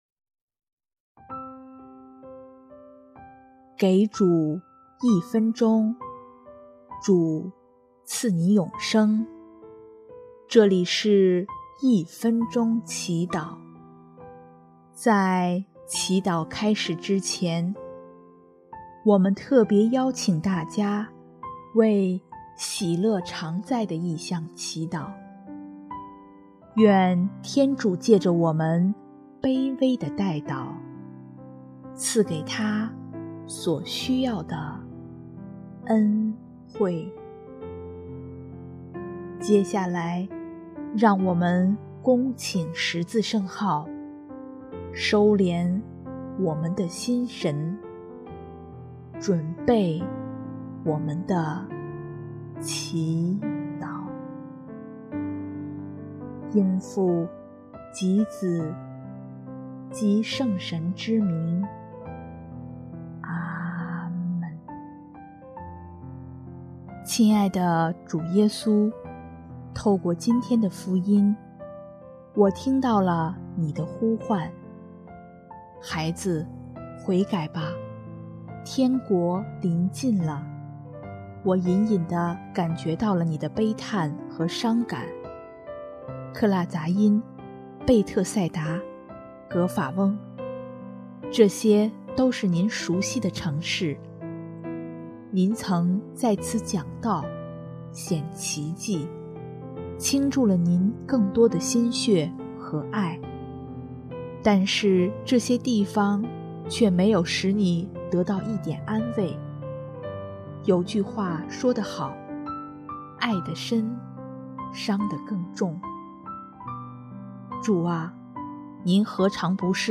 【一分钟祈祷】|10月6日 回归主羊栈，传扬主福音！